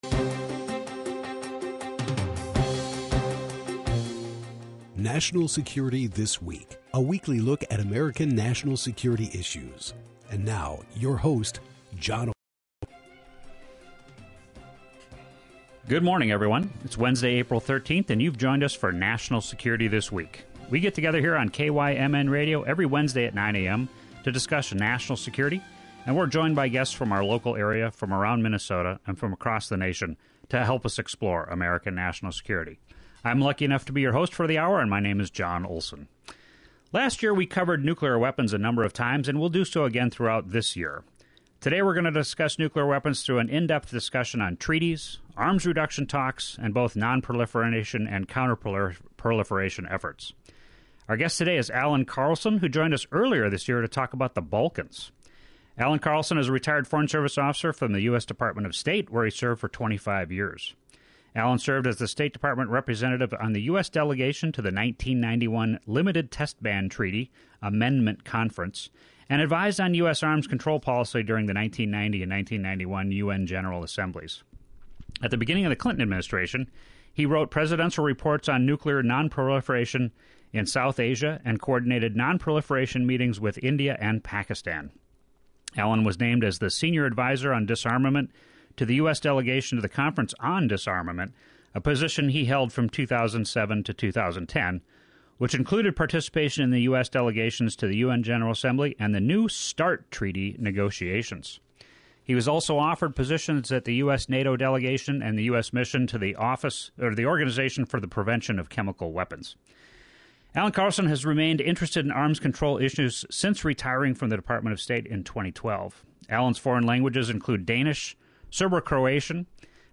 KYMN Radio · Northfield, MN · AM 1080 & FM 95.1